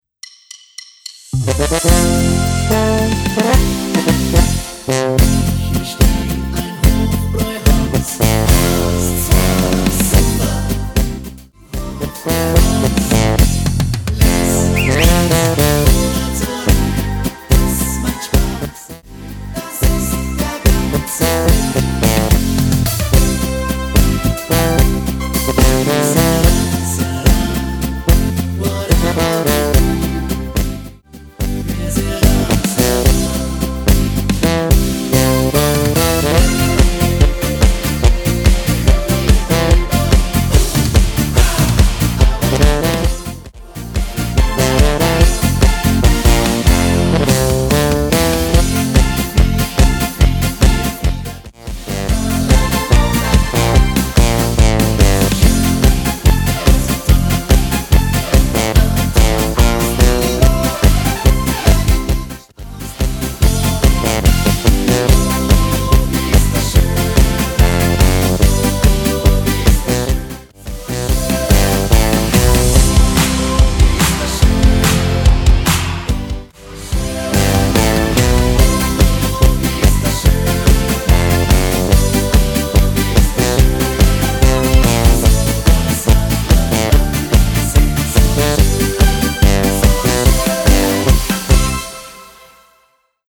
Art: Volkstümliche Version
Tonart: GCFB Harmonika HPB Version mit Chor
im volkstümlichen Stil produziert